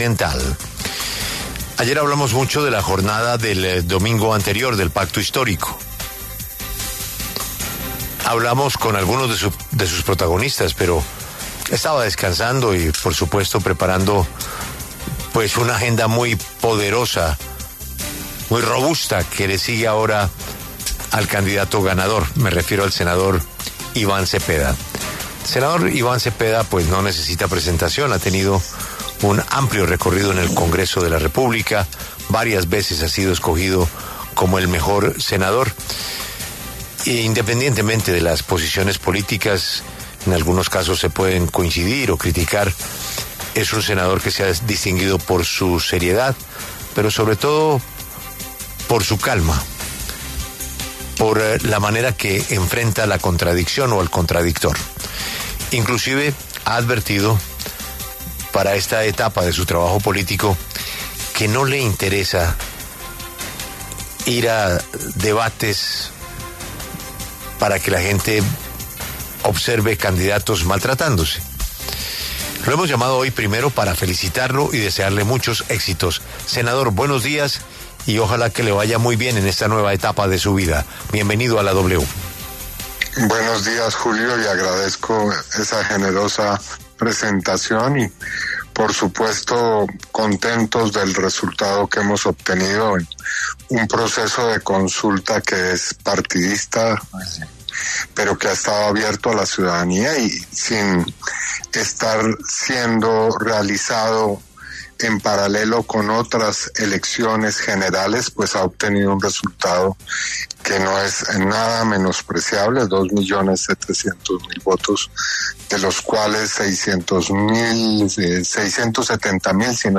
Iván Cepeda, senador y ganador de la consulta del Pacto Histórico, habló en La W sobre la jornada electoral del domingo 26 de octubre donde obtuvo más de 1 millón de votos.
Iván Cepeda, ganador de la consulta presidencial del Pacto Histórico, habla en La W